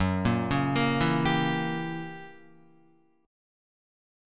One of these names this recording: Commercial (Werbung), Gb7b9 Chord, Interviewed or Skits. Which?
Gb7b9 Chord